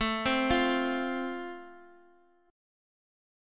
Am#5 chord